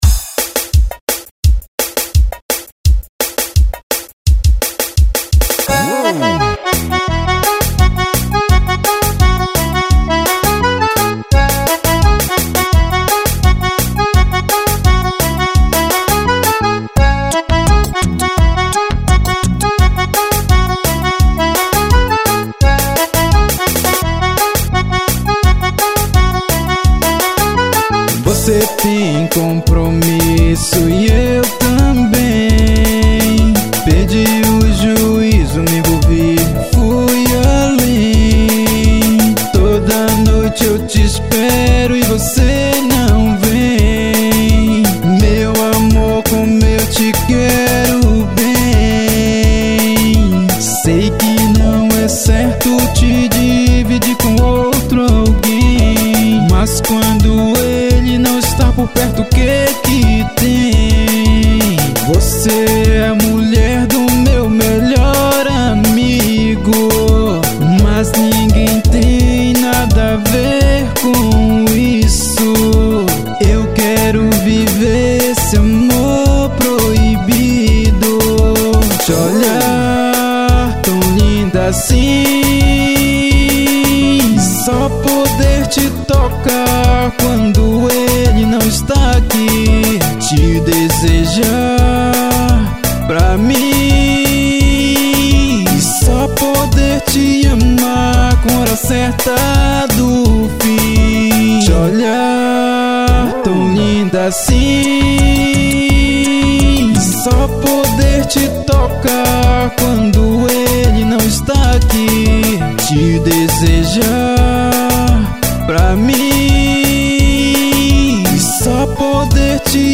EstiloTecnobrega